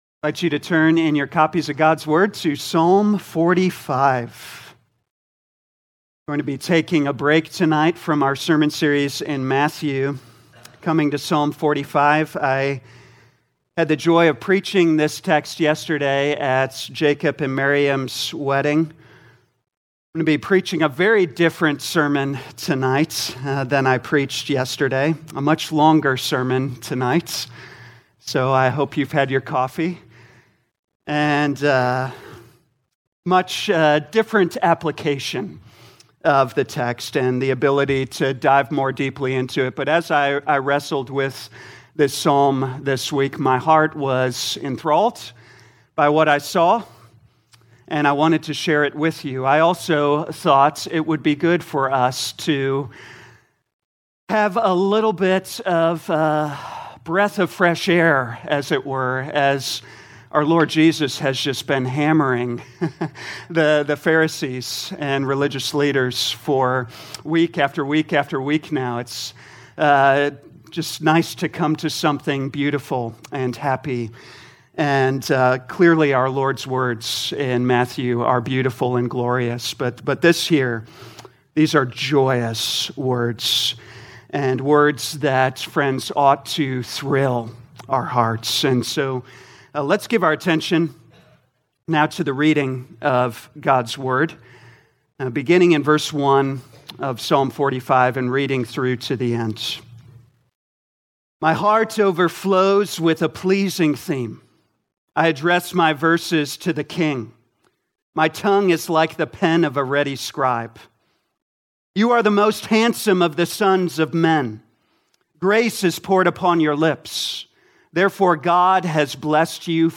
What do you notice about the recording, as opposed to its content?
2024 Psalms Evening Service Download: Audio Notes Bulletin All sermons are copyright by this church or the speaker indicated.